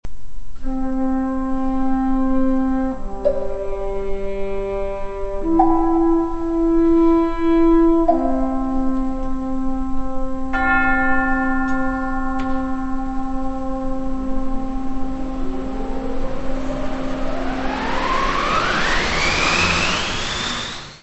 : stereo; 12 cm + folheto
piano
trompa
xilorimba
glockenspiel
Área:  Música Clássica